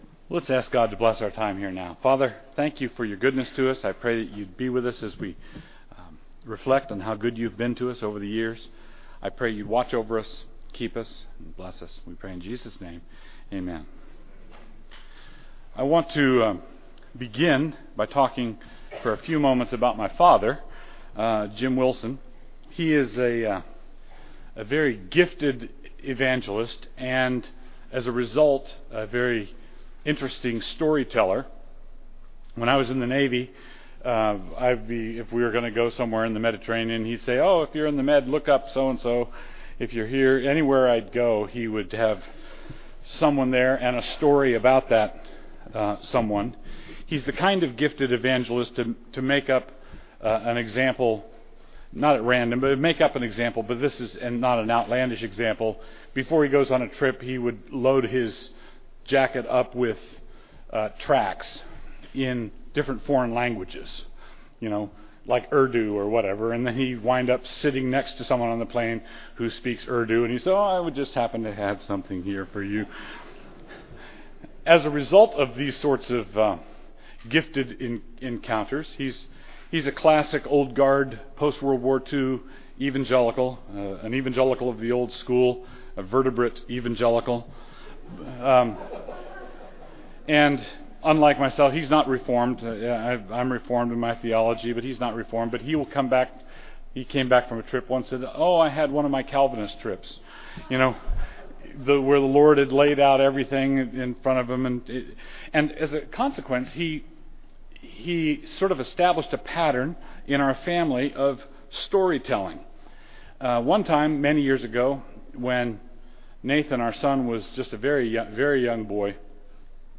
2009 Foundations Talk | 1:03:31 | All Grade Levels, Culture & Faith
Jan 31, 2019 | All Grade Levels, Conference Talks, Culture & Faith, Foundations Talk, Library, Media_Audio | 0 comments